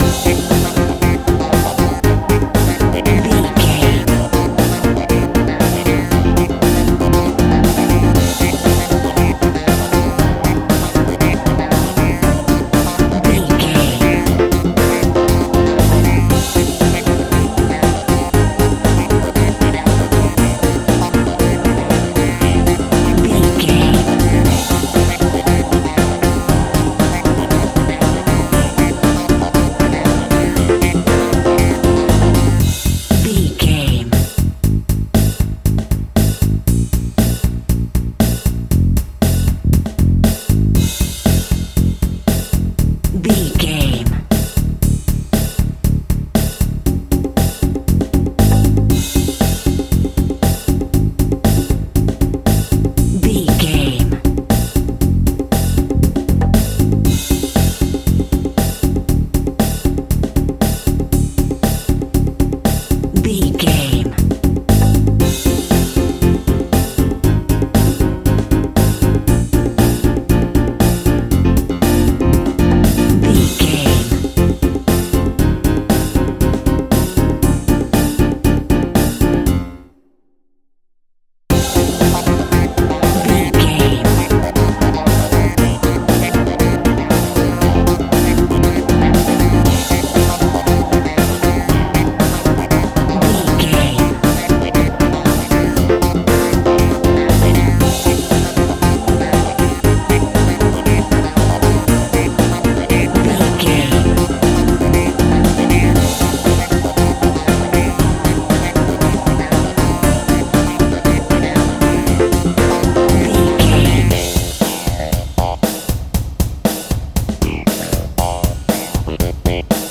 Ionian/Major
disco
soul jazz
electric guitar
bass guitar
drums
hammond organ
fender rhodes
percussion